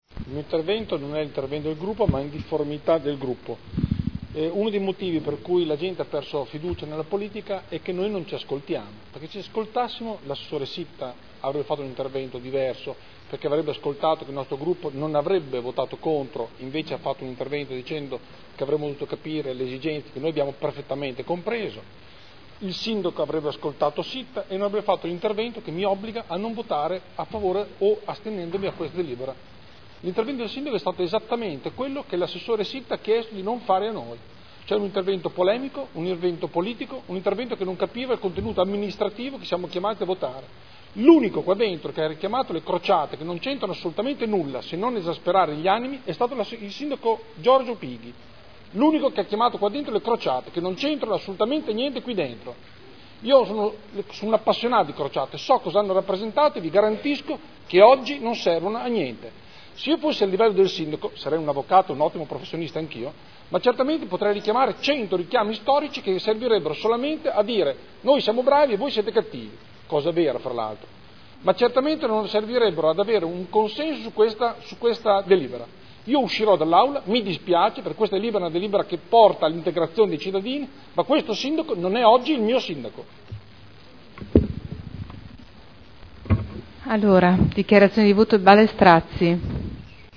Dichiarazione di voto. Nulla osta al rilascio o presentazione di titolo abilitativo edilizio in deroga agli strumenti urbanistici (art. 15 L.R. 31/2002 e art. 31.23 RUE) – Richiesta presentata dalla Comunità Islamica di Modena (Commissione consiliare del 7 febbraio 2012)